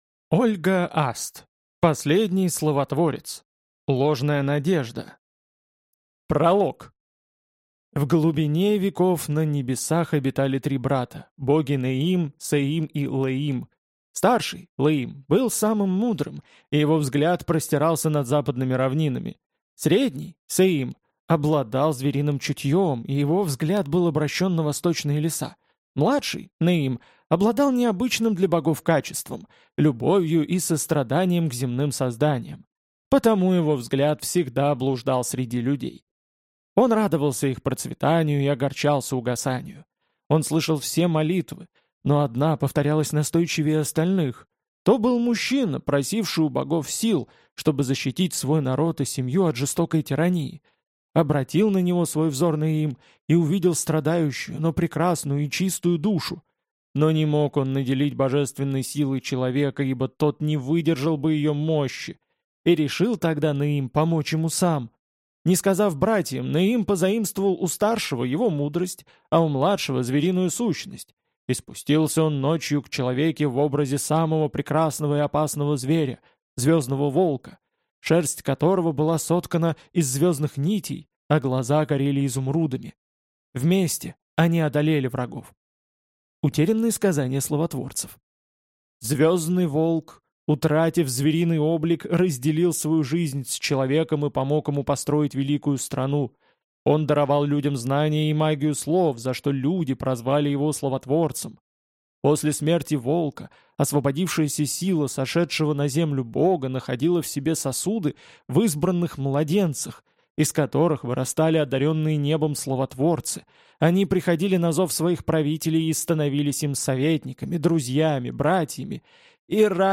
Аудиокнига Последний Словотворец. Ложная надежда | Библиотека аудиокниг